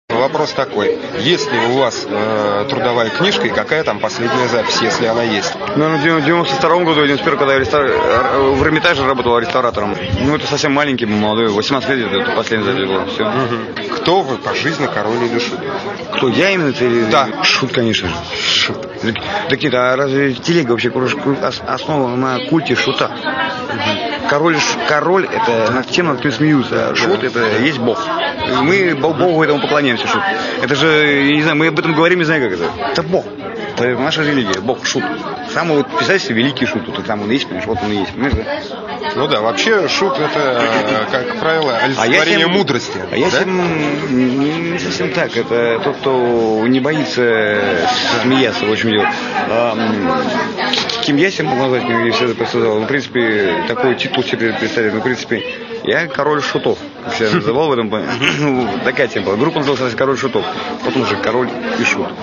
Интервью с Михаилом Горшеневым